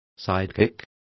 Complete with pronunciation of the translation of sidekick.